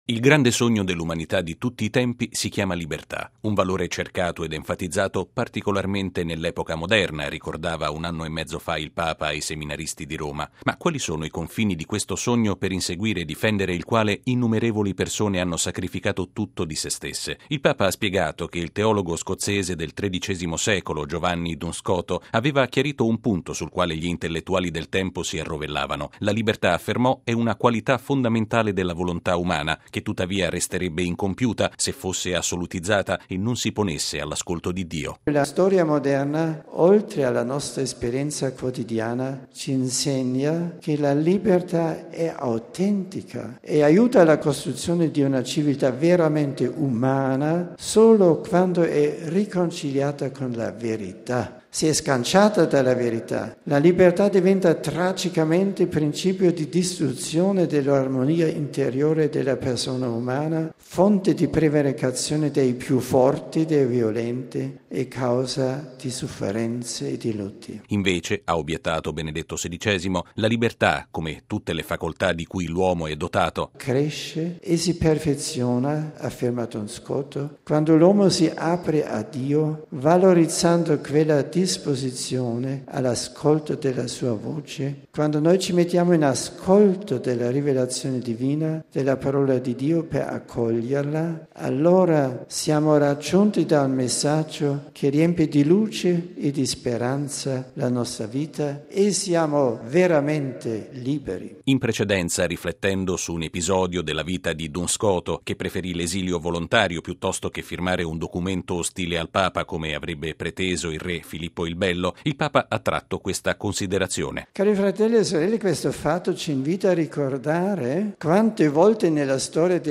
Il Papa all'ultima udienza generale, prima della pausa estiva a Castel Gandolfo: la fedeltà a Cristo può costare ostilità e persecuzioni
Uno di questi testimoni è stato il famoso teologo medievale Giovanni Duns Scoto, al quale Benedetto XVI ha dedicato la catechesi dell’udienza generale di questa mattina, nell'Aula Paolo VI in Vaticano.
(applausi)